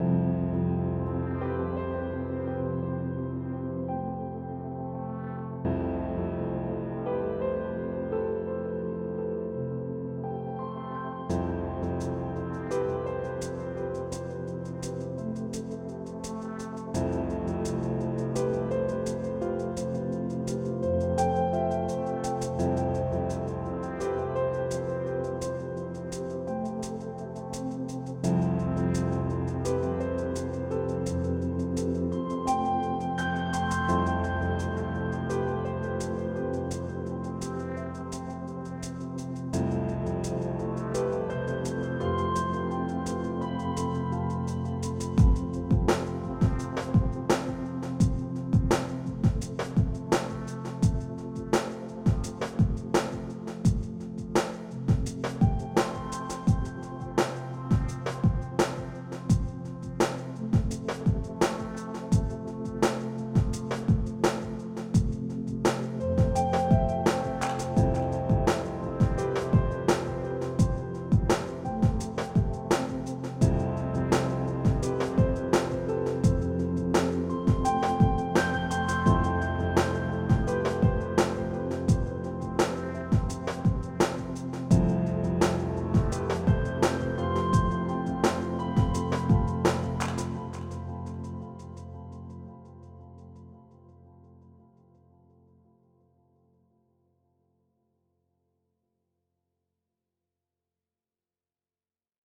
Category 🎵 Relaxation